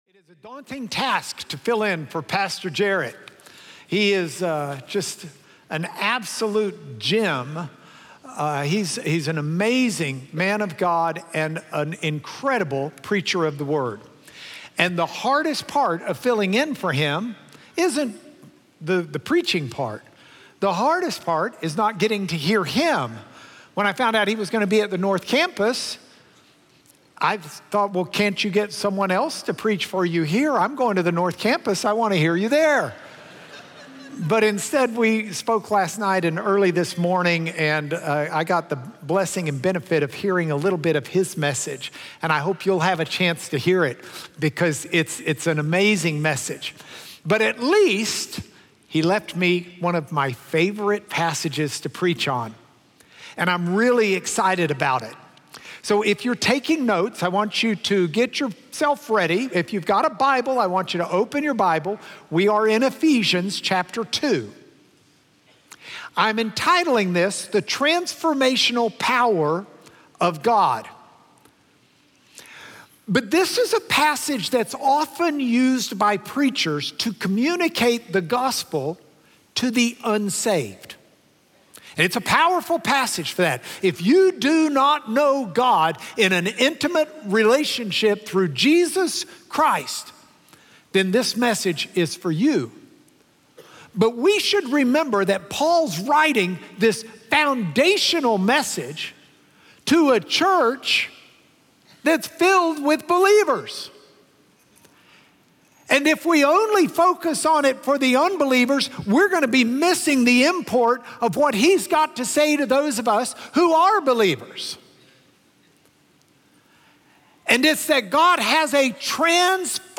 Sunday Sermons – Media Player